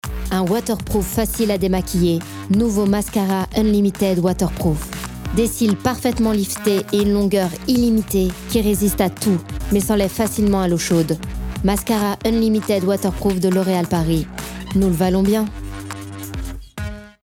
Voix off
Bande démo